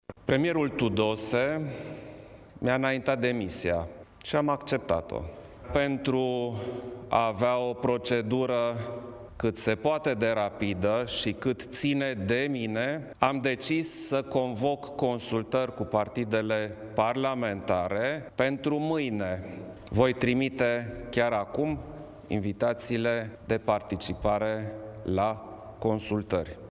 Șeful statului a precizat că a acceptat demisia lui Mihai Tudose, anunțând totodată că mâine va începe consultările cu reprezentanții partidelor parlamentare: